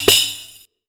TC PERC 11.wav